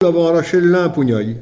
Saint-Hilaire-des-Loges
Catégorie Locution